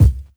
Medicated Kick 22.wav